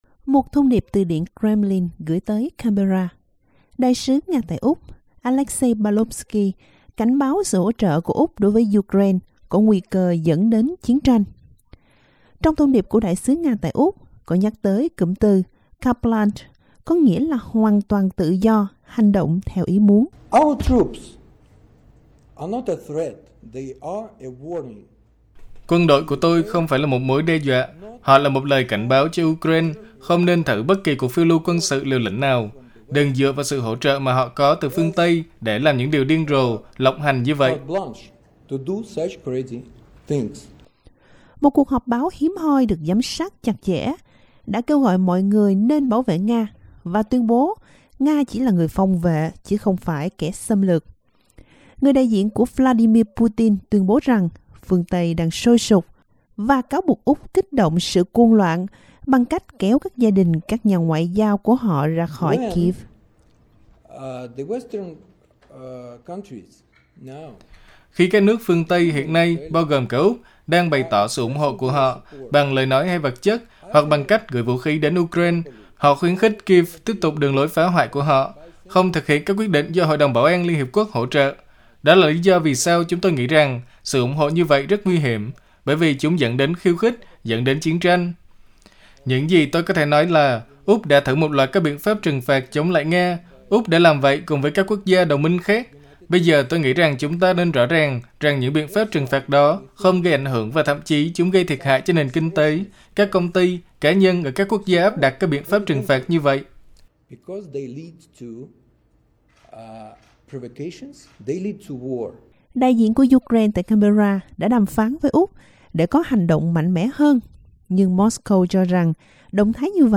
Russias Ambassador to Australia Dr. Alexey Pavlovsky at a press conference in Canberra, Friday, January 28, 2022.